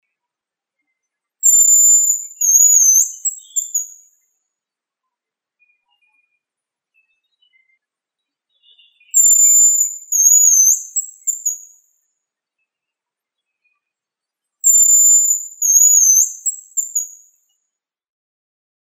Звуки рябчика